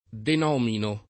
denomino [ den 0 mino ]